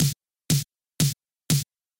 Literally any sample will do, but for this example I’m using a nice heavy snare hit, with plenty of low end as I’ve always found this to be a winner.